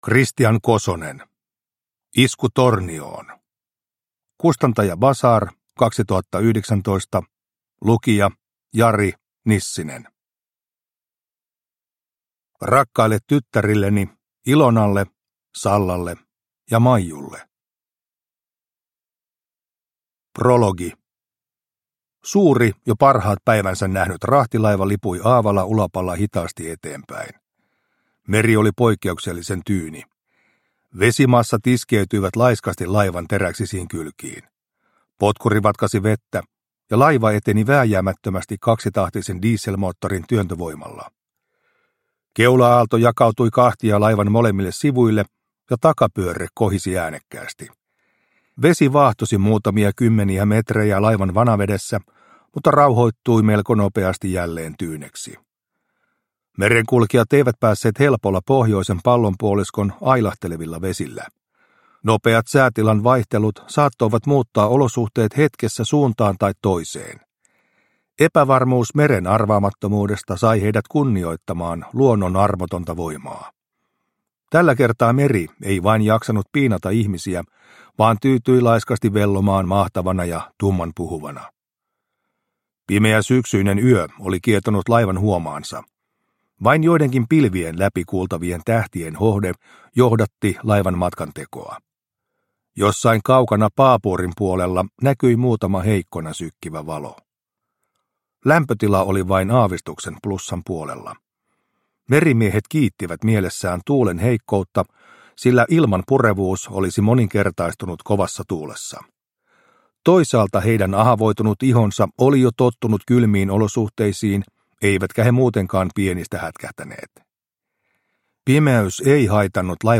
Isku Tornioon – Ljudbok – Laddas ner